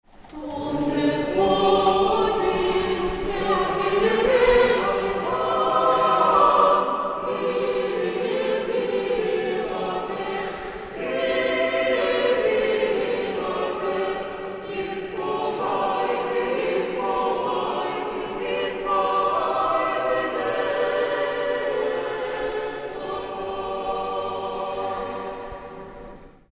Архиерейский хор кафедрального собора Вологды